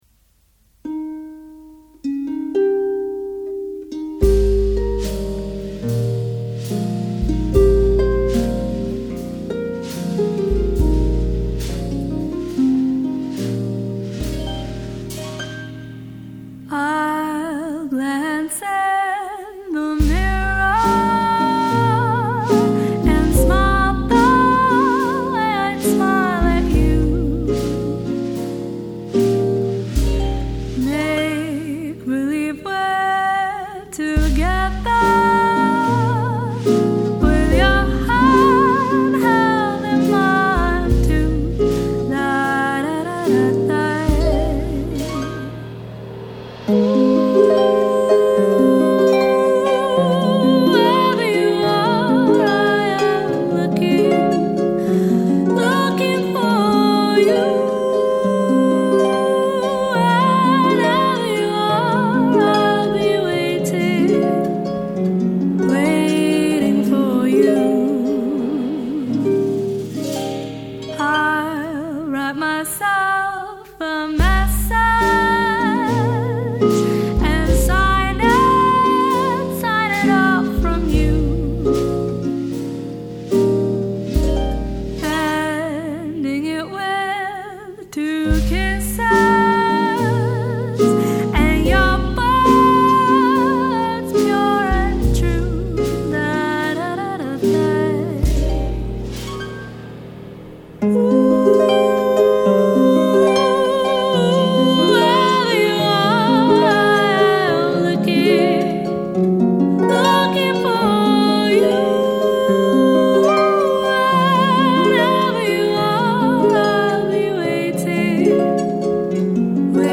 An award-winning harpist and vocalist.